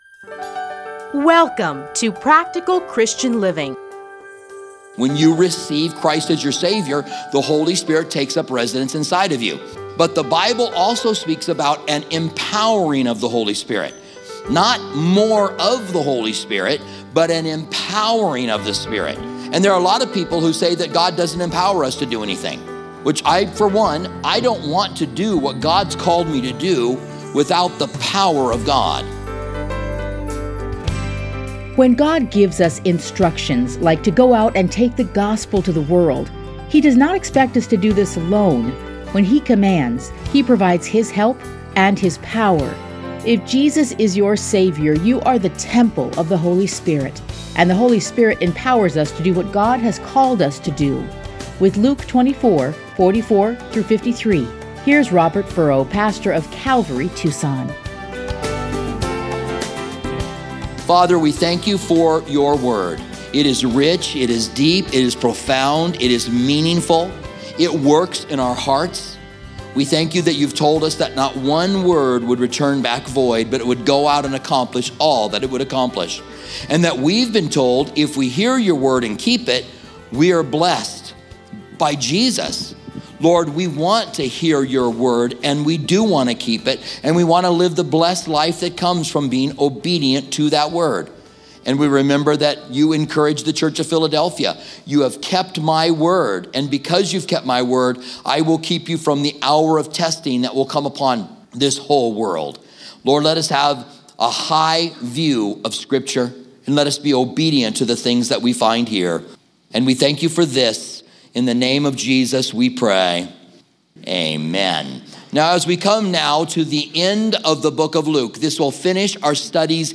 Listen to a teaching from Luke 24:44-53.